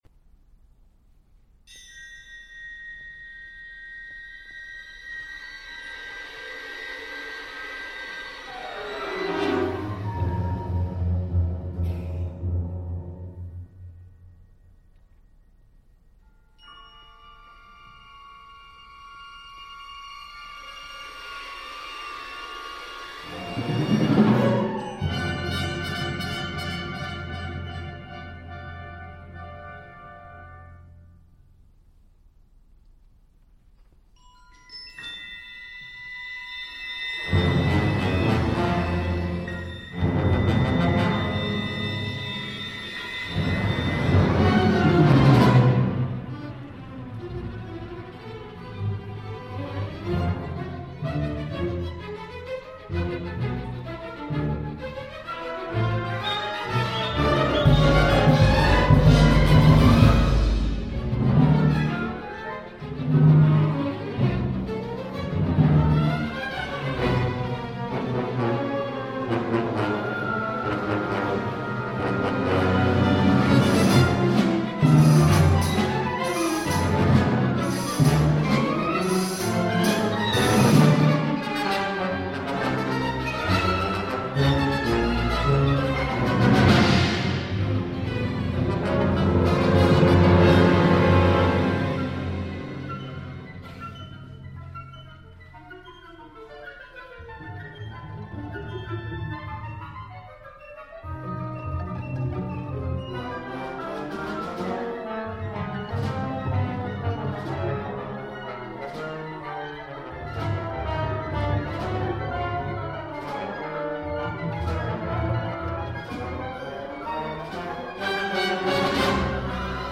for orchestra https